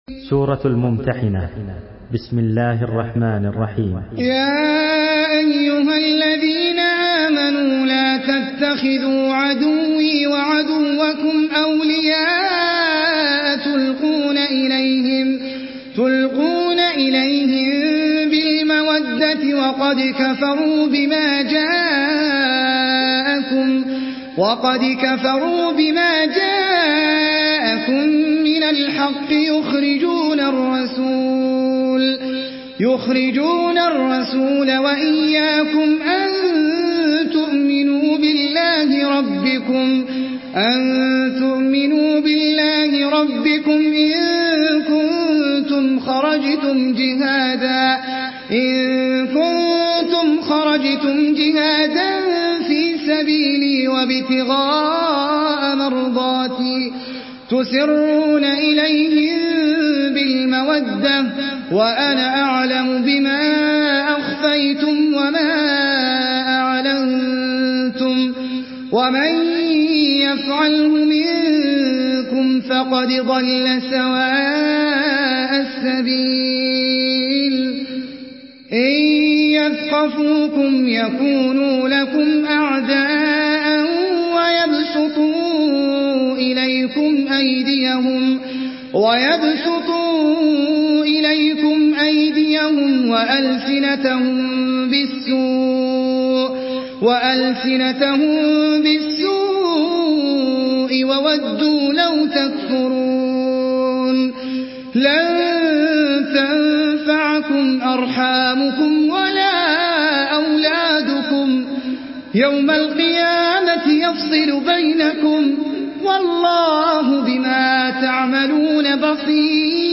Surah Al-Mumtahinah MP3 by Ahmed Al Ajmi in Hafs An Asim narration.
Murattal Hafs An Asim